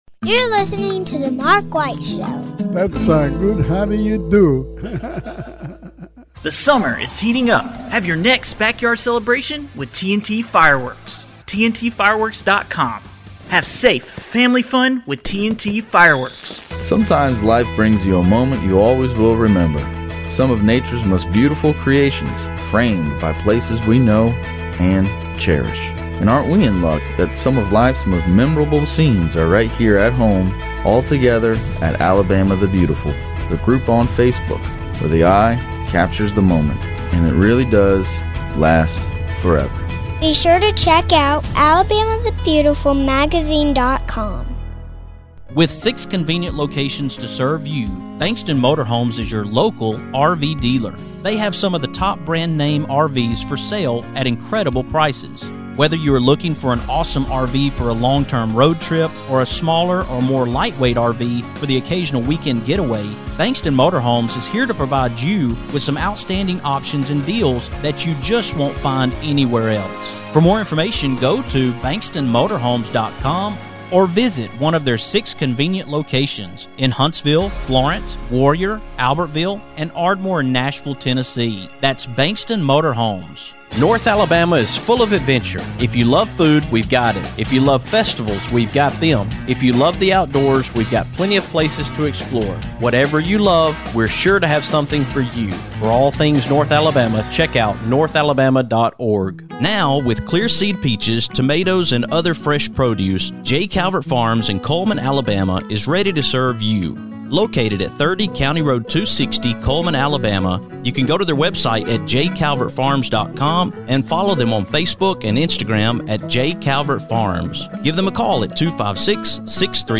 After that, I have three more guests from Covington Veterans Foundation!